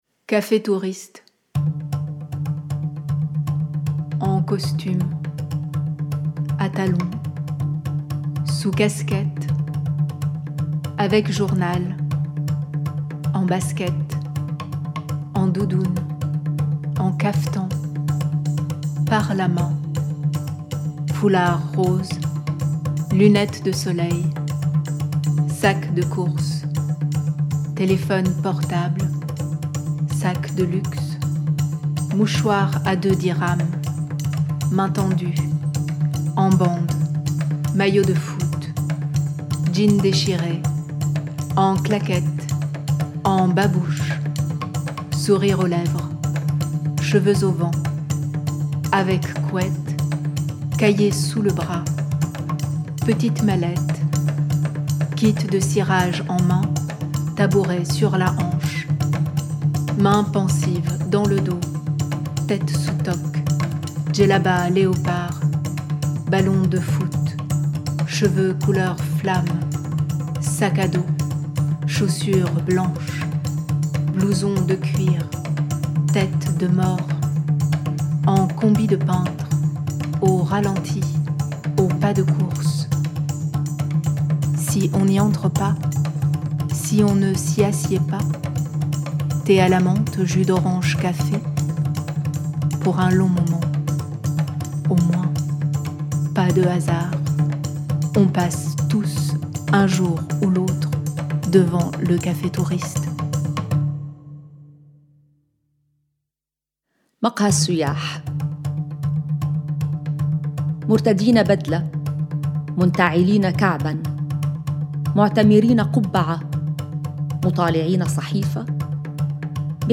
création musicale et mixage
lecture en français
lecture en arabe
05-CAFE-TOURISTES-francais-et-arabe.mp3